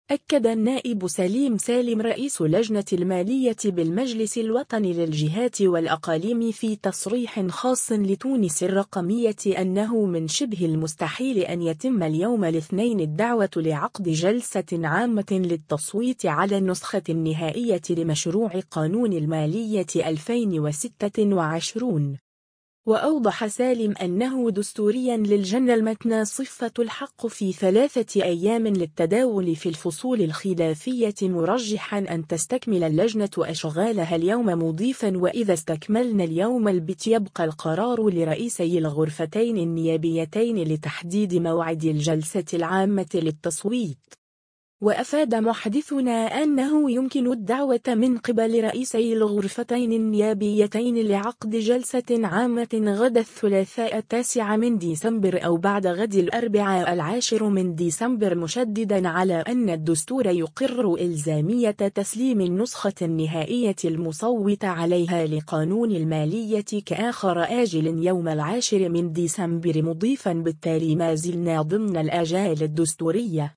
أكد النائب سليم سالم رئيس لجنة المالية بالمجلس الوطني للجهات والأقاليم في تصريح خاص لـ”تونس الرقمية” أنه من شبه المستحيل أن يتم اليوم الاثنين الدعوة لعقد جلسة عامة للتصويت على النسخة النهائية لمشروع قانون المالية 2026.